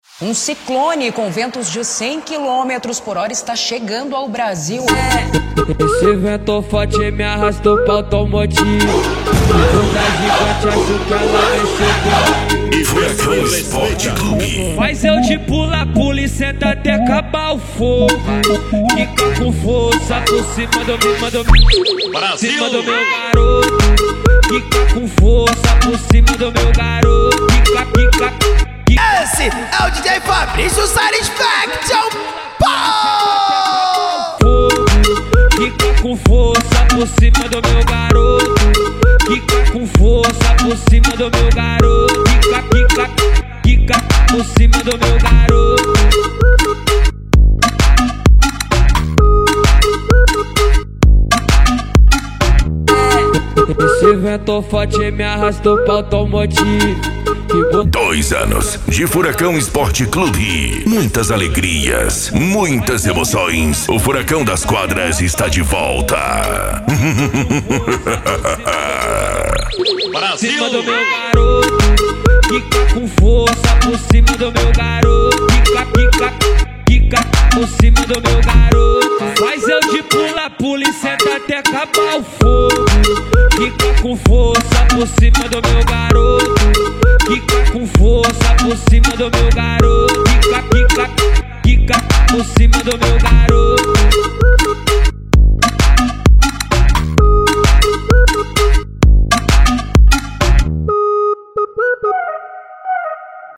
Funk
SERTANEJO
Sertanejo Raiz